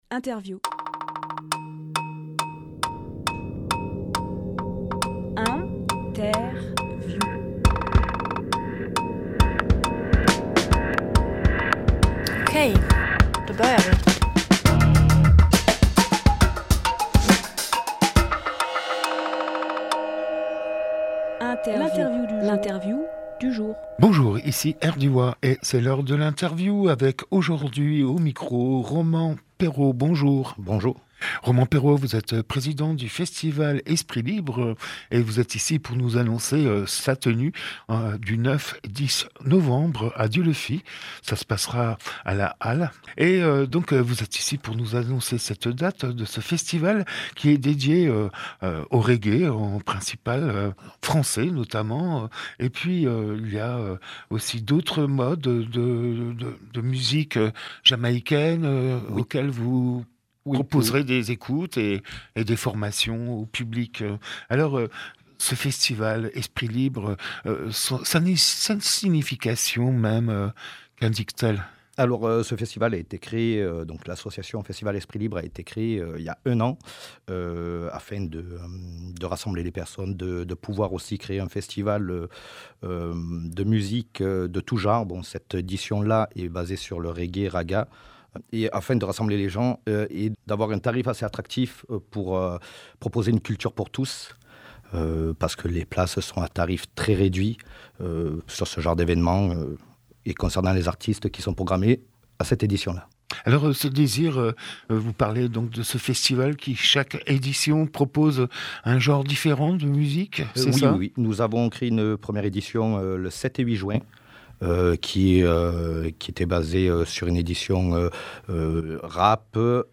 Emission - Interview Festival Esprit Libre à Dieulefit Publié le 14 octobre 2024 Partager sur…
08.10.24 Lieu : Studio Rdwa Durée